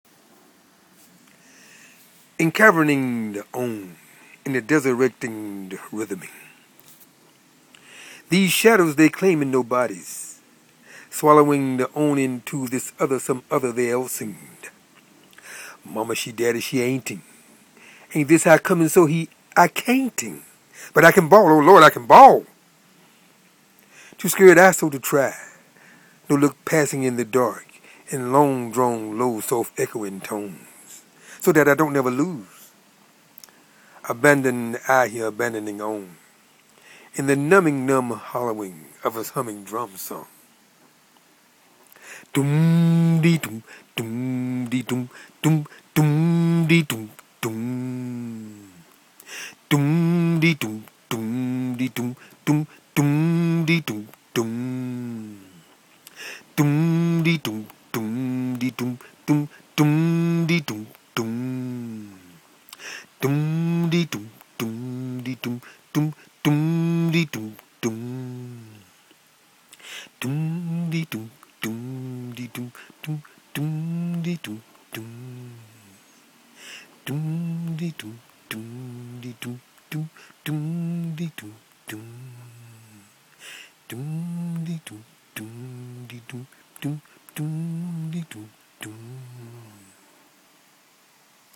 en-cavernimg-d on in the . . . (a tonal drawing written in poetic form)